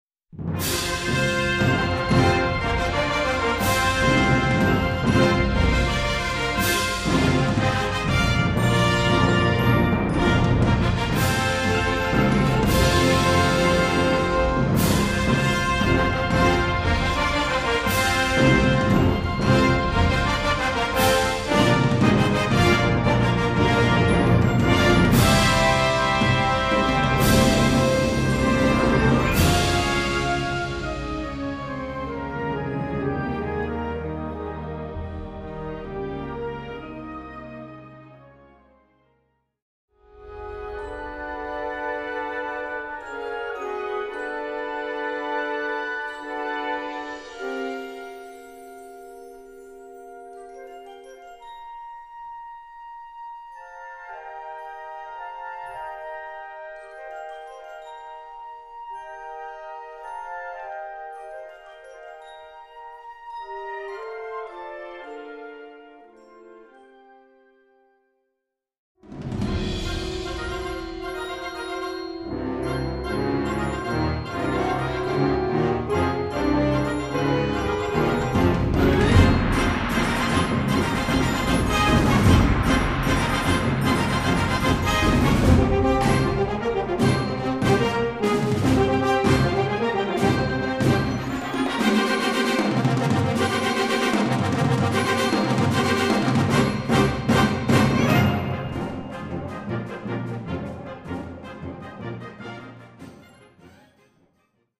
Gattung: Sinfonisches Gedicht für Blasorchester
Besetzung: Blasorchester
in seinem charakteristischen dramatischen Stil um